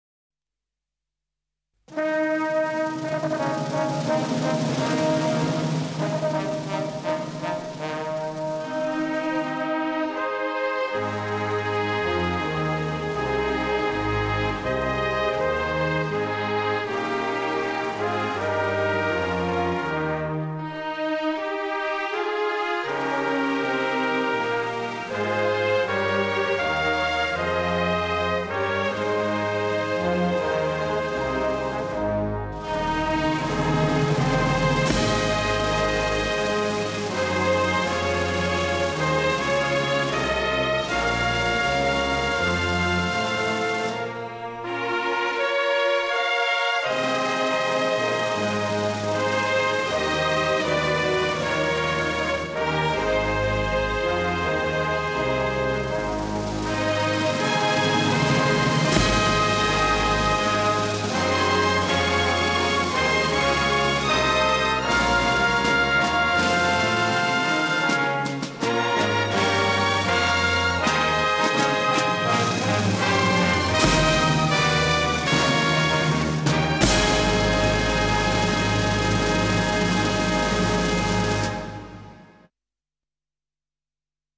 Lagu-Negaraku-Minus-One.mp3